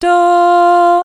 Hard Melody 2: Tonic